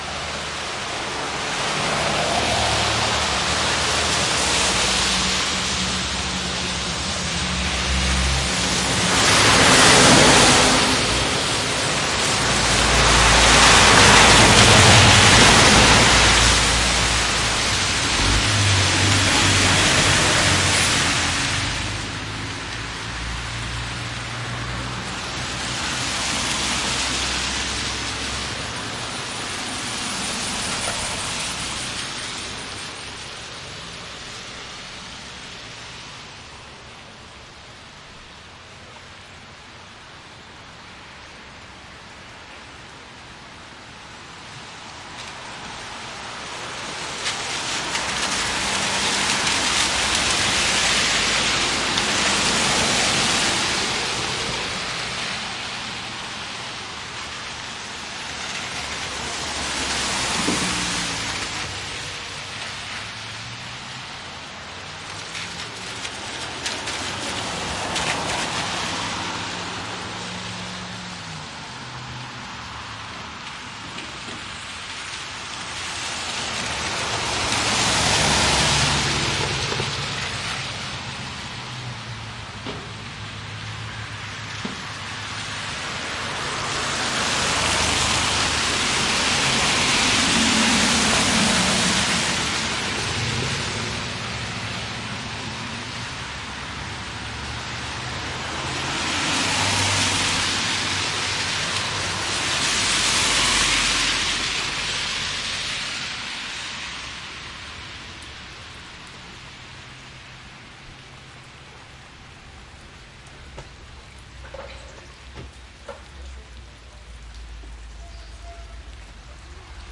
蒙特利尔 " 交通 中等湿路 + 卡车2 蒙特利尔，加拿大
描述：交通媒介湿路+ truck2蒙特利尔，Canada.flac
Tag: 蒙特利尔 潮湿 道路 交通 卡车 中型 加拿大